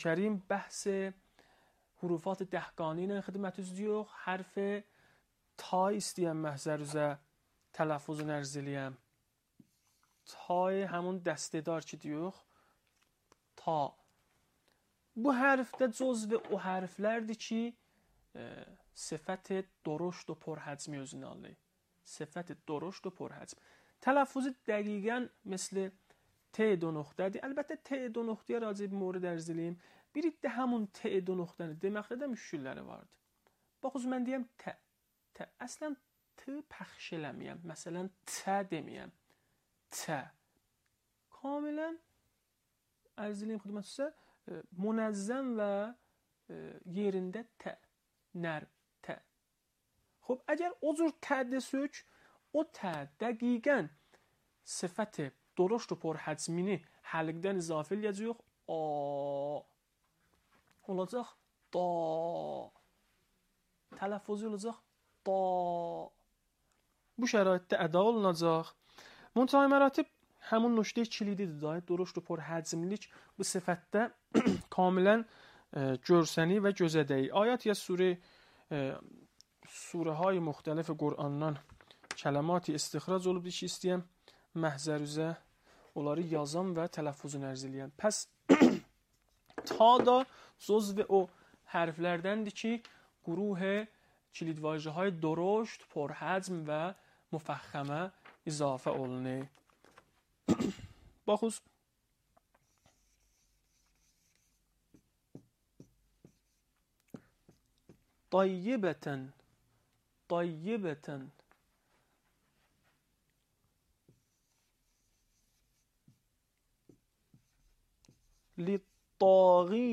صوت | آموزش مجازی قرآن به زبان ترکی / 3